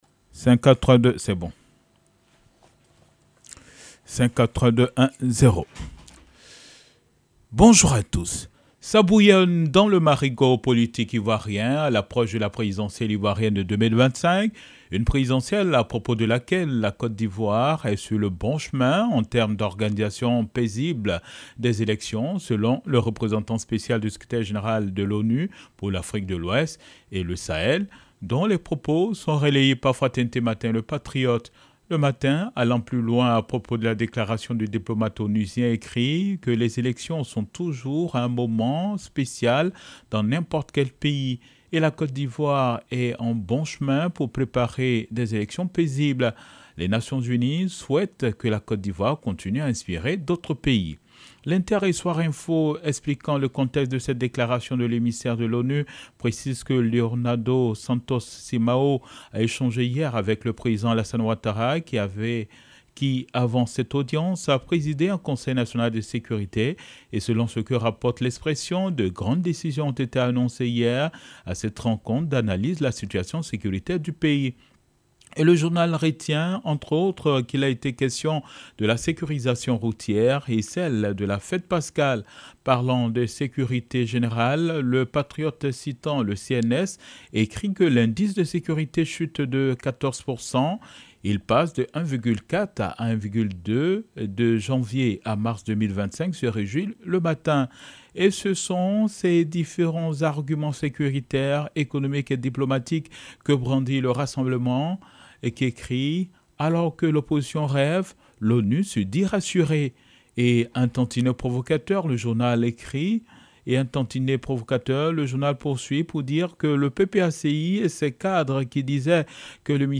Revue de Presse du 18 avril 2025 - Site Officiel de Radio de la Paix